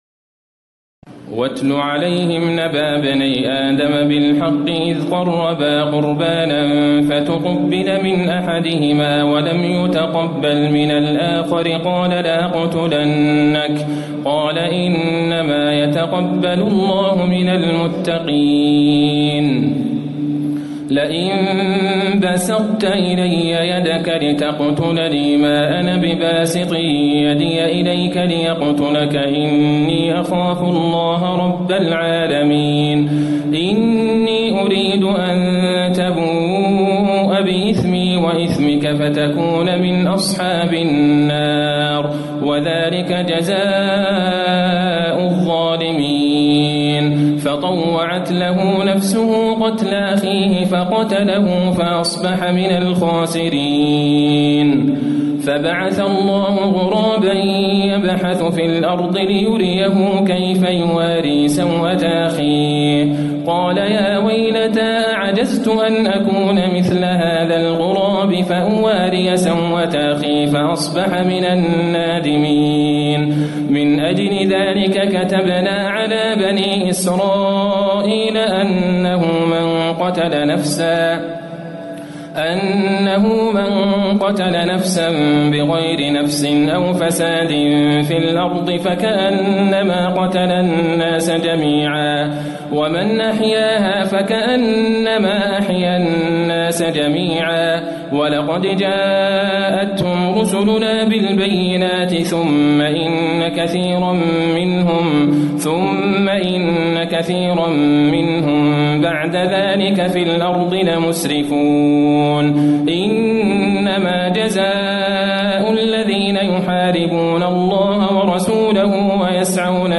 تراويح الليلة السادسة رمضان 1437هـ من سورة المائدة (27-96) Taraweeh 6 st night Ramadan 1437H from Surah AlMa'idah > تراويح الحرم النبوي عام 1437 🕌 > التراويح - تلاوات الحرمين